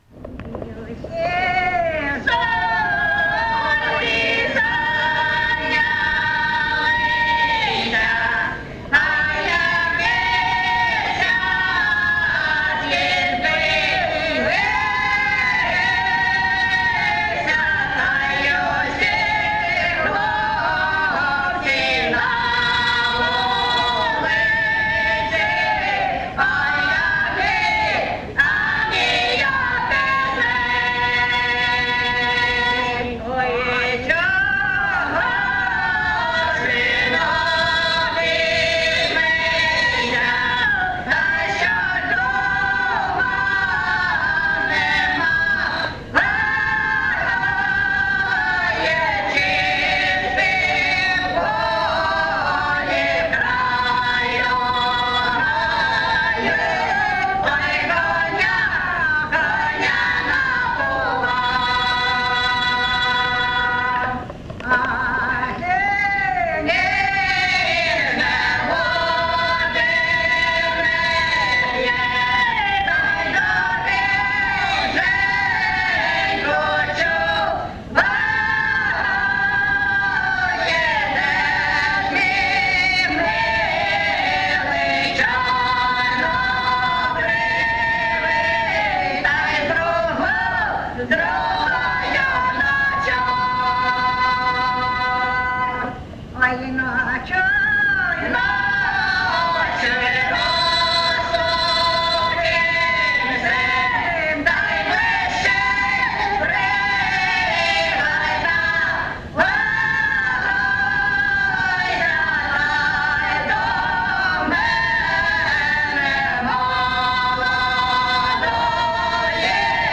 ЖанрПісні з особистого та родинного життя
Місце записус. Очеретове, Валківський район, Харківська обл., Україна, Слобожанщина